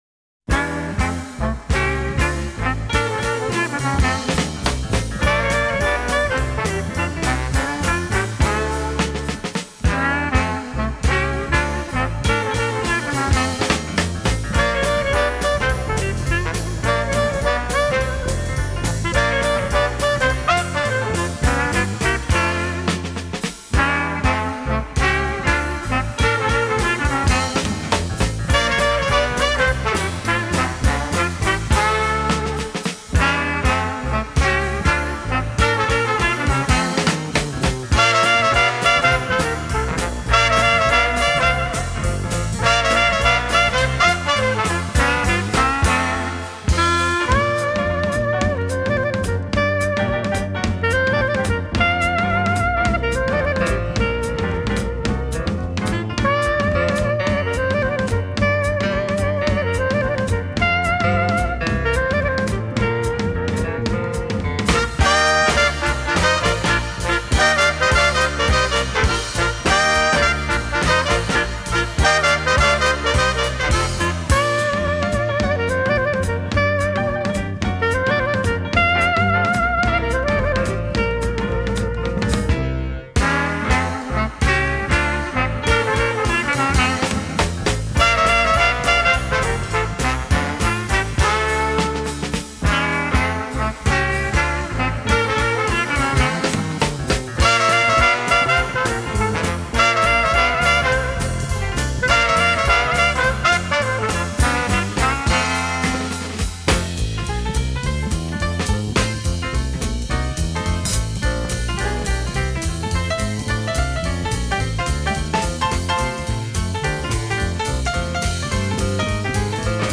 Веселые диксиленды послушаем .
диксиленд